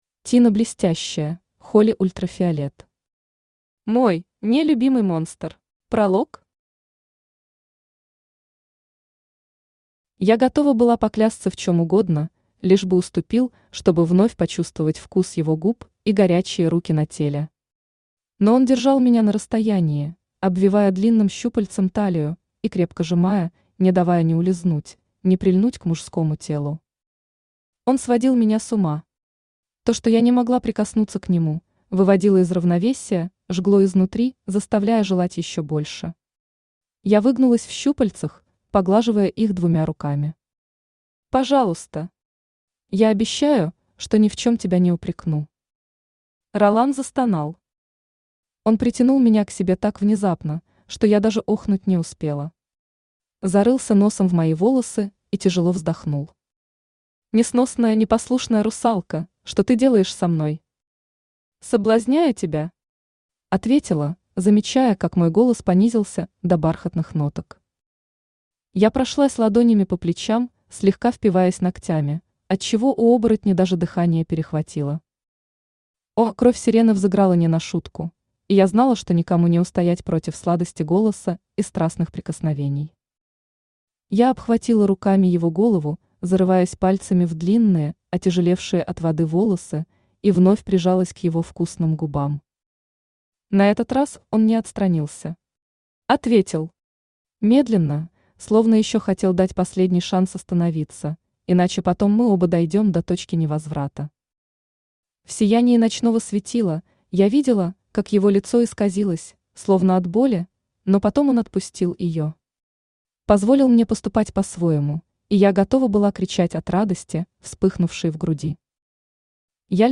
Аудиокнига Мой (не)любимый монстр | Библиотека аудиокниг
Aудиокнига Мой (не)любимый монстр Автор Тина Блестящая Читает аудиокнигу Авточтец ЛитРес.